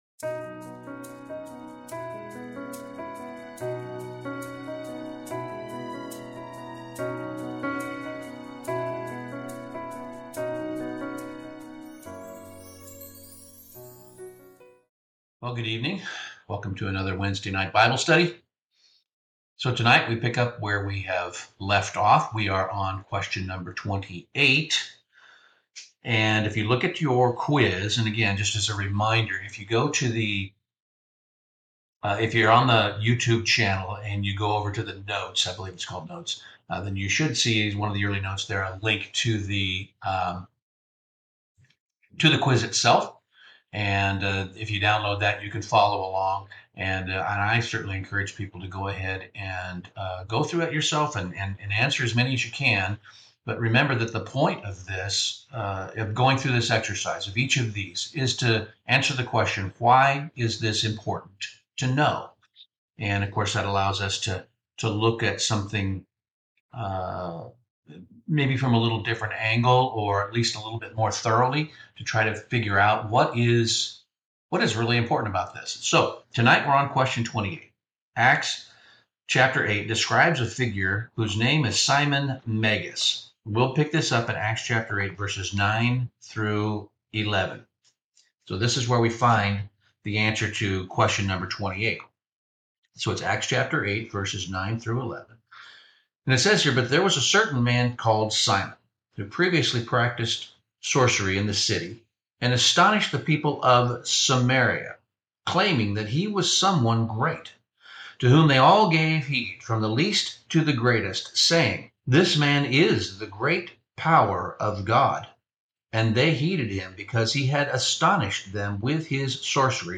NW Bible Study - Bible Quiz #28 - Simon Magus | United Church of God